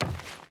Footsteps / Wood / Wood Run 1.ogg
Wood Run 1.ogg